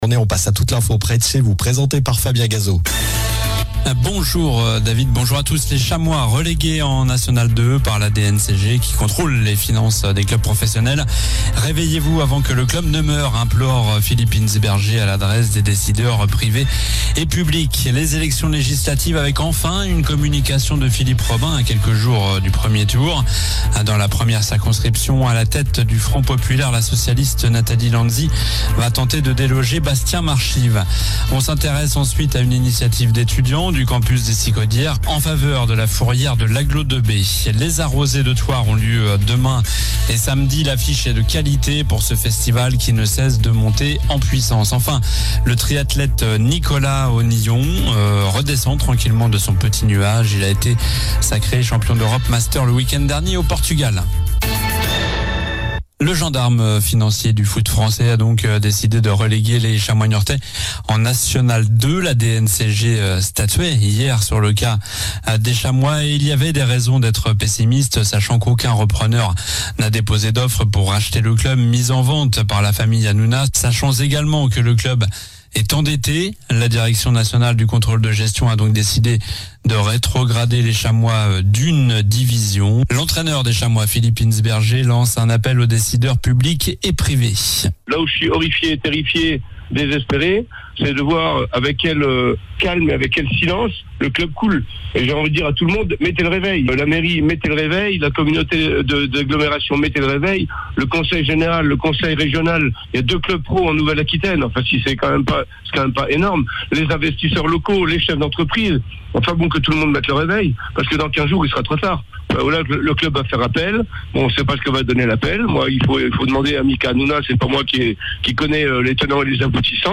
Journal du jeudi 27 juin (midi)